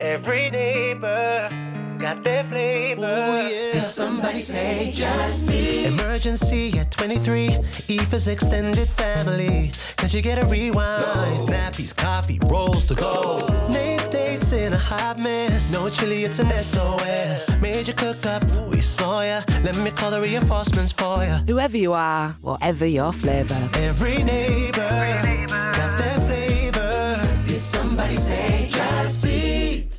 audio ads
garage-infused anthem
More than just a catchy tune